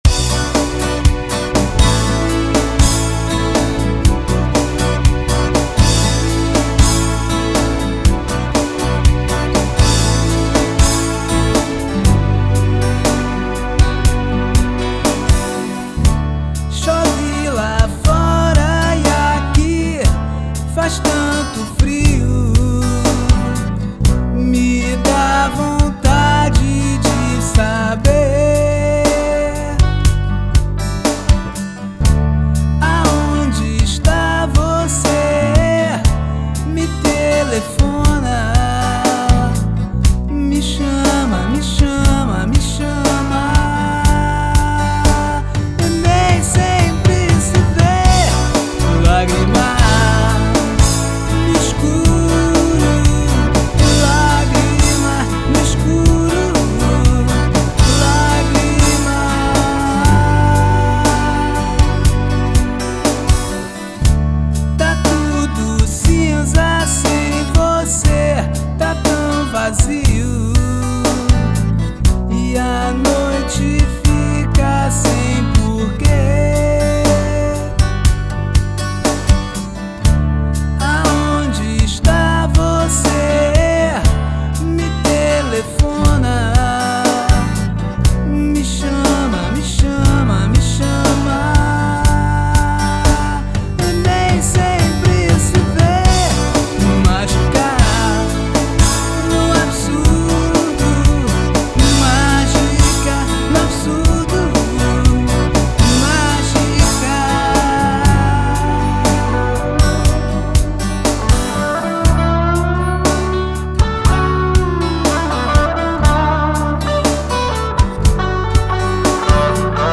Pop/Rock song
acoustic guitar
bass, e I stay with the keyboards.
we recorded all in one night at Home Studio